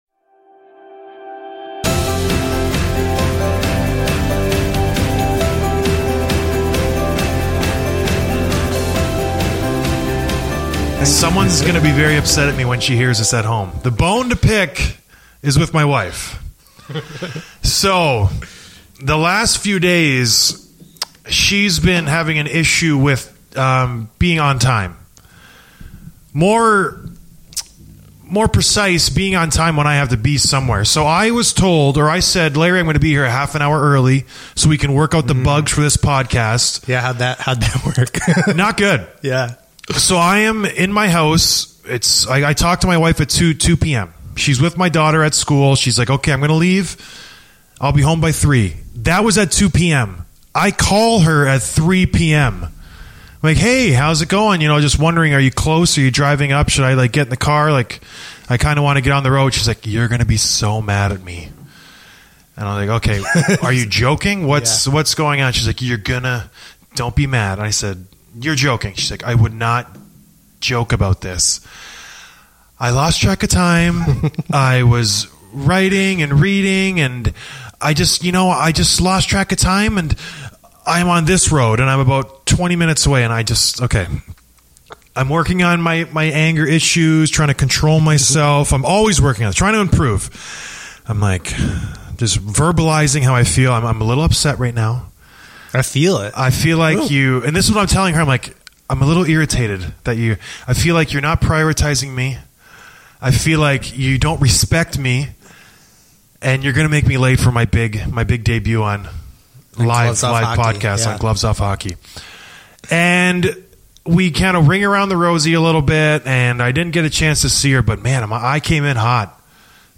It's Wednesday Afternoon LIVE!
In case you missed it, this episode was filmed and recorded during a super fun livestream! We go through game one, talk a little basketball and answer some live questions!